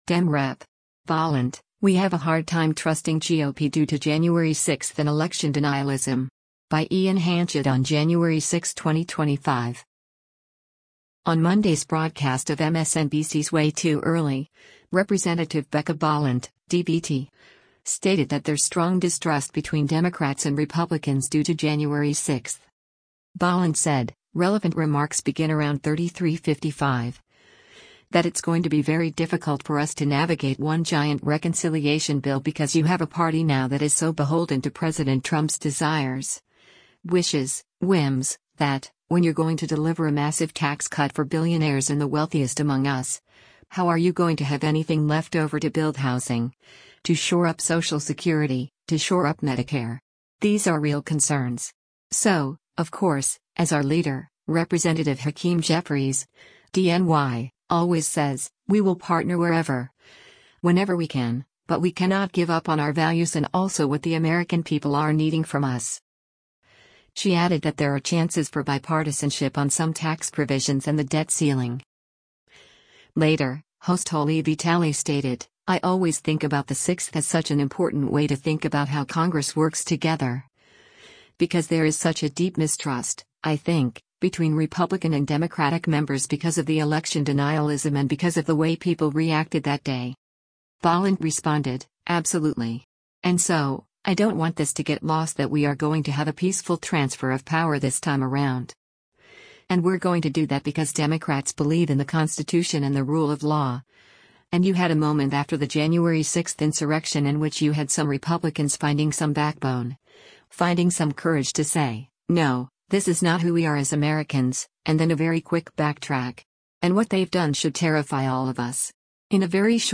On Monday’s broadcast of MSNBC’s “Way Too Early,” Rep. Becca Balint (D-VT) stated that there’s strong distrust between Democrats and Republicans due to January 6.